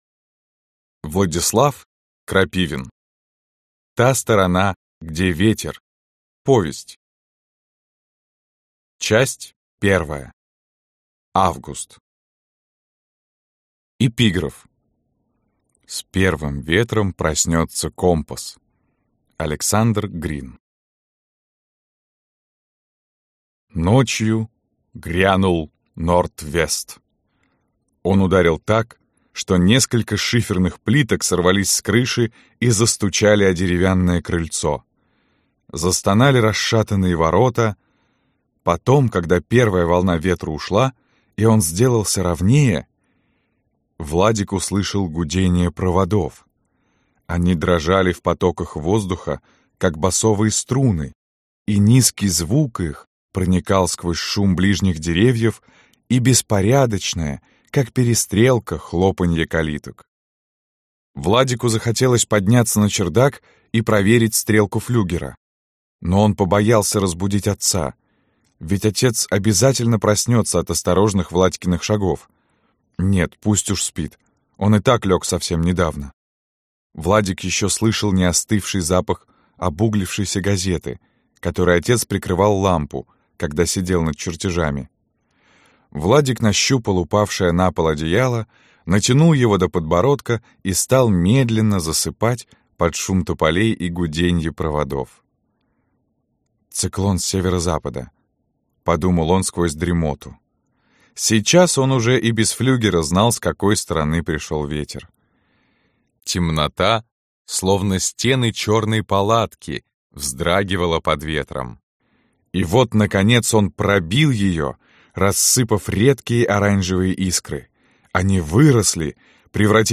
Аудиокнига Та сторона, где ветер | Библиотека аудиокниг